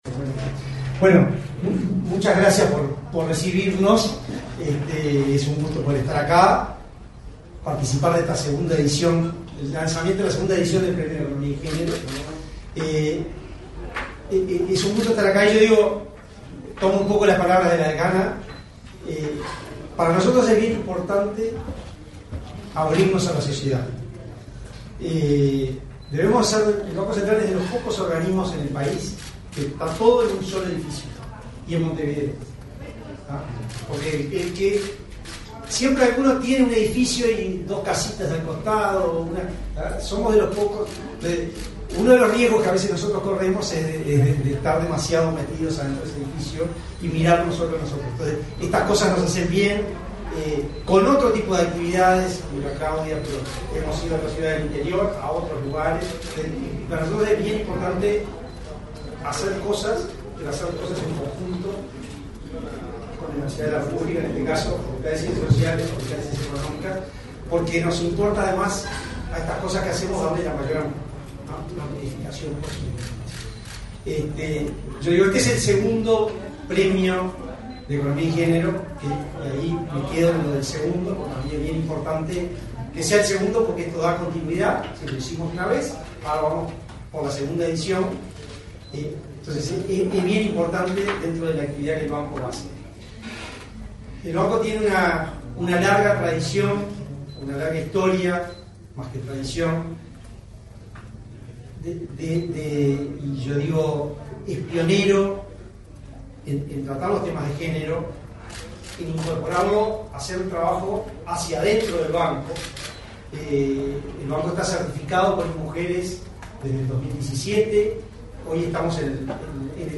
Palabras del presidente del BCU, Diego Labat
Palabras del presidente del BCU, Diego Labat 26/04/2024 Compartir Facebook X Copiar enlace WhatsApp LinkedIn El Banco Central del Uruguay (BCU) realizó, este 25 de abril, el lanzamiento de la convocatoria a la segunda edición del Premio Economía y Género en el Sistema Financiero. En la oportunidad, el presidente de la institución, Diego Labat, hizo uso de la palabra.